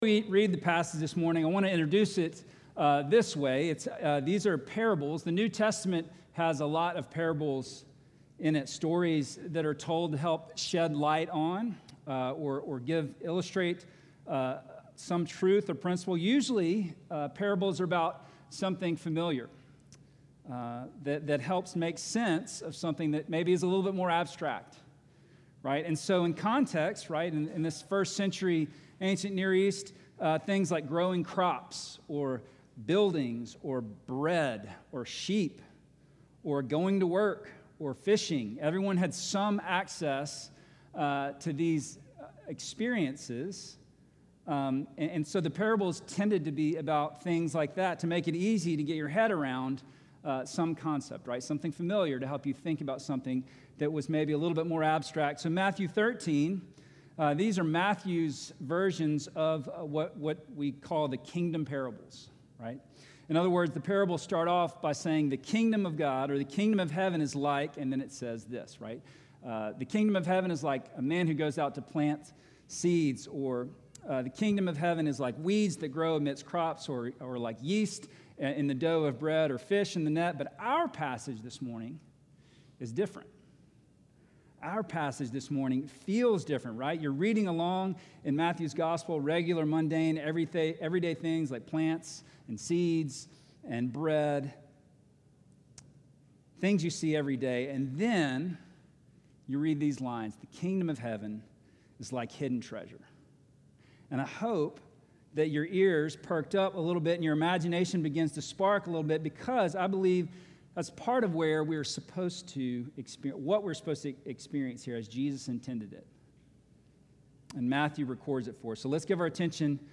Sermon from July 20